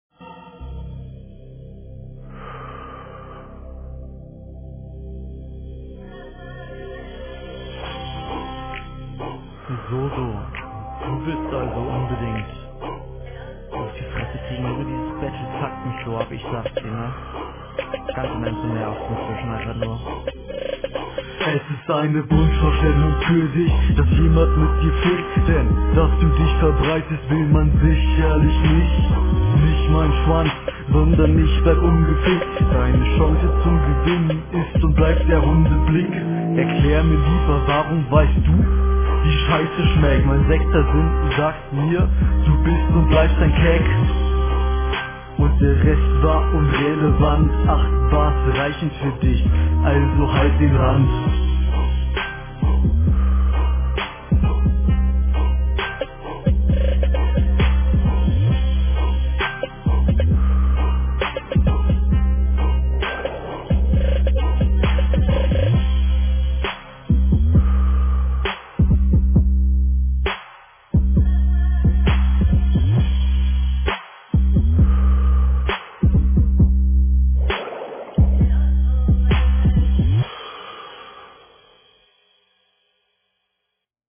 Viel zu leise abgemischt, versteh nicht wirklich was, du gibst dir hier leider gar keine …
Okay hier versteht man leider gar nichts.